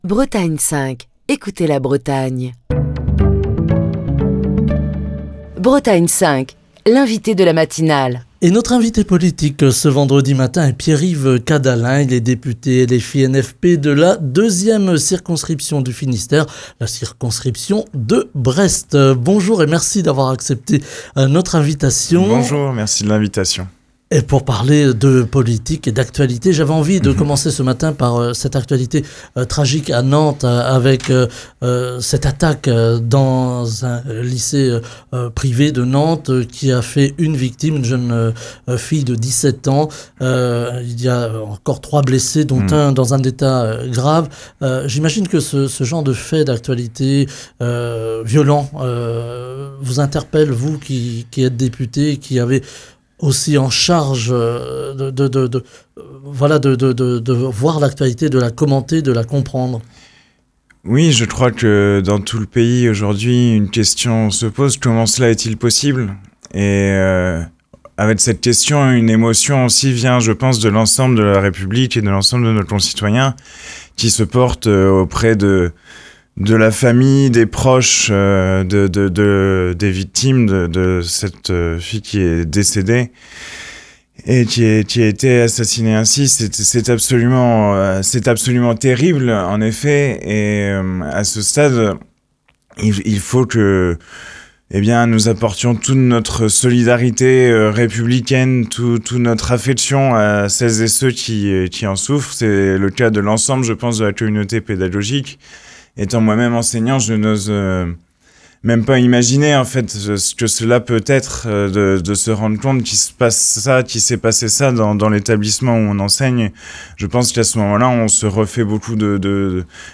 Pierre-Yves Cadalen, député La France Insoumise - Nouveau Front Populaire de la deuxième circonscription du Finistère, était l’invité politique de la matinale de Bretagne 5 ce vendredi.